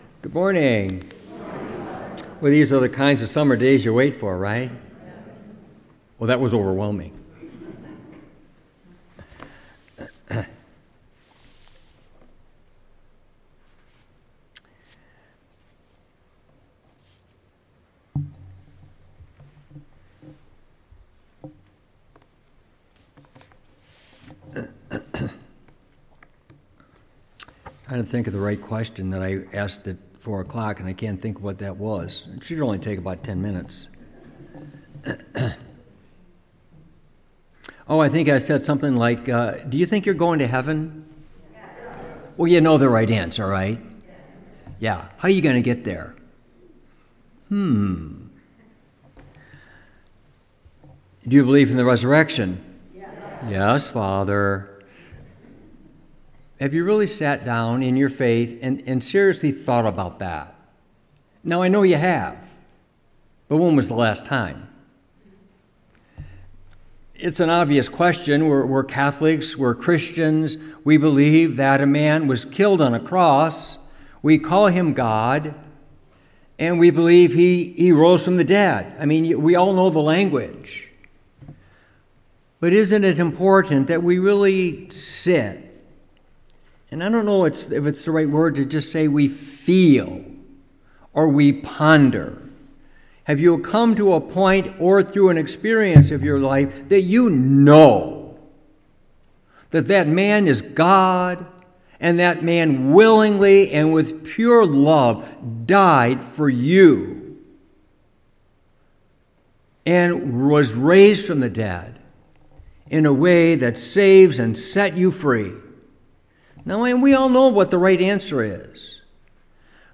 Homily, June 29, 2025 ,Feast of St. Peter and Paul
Homily-Feast-of-St-Peter-Paul-25.mp3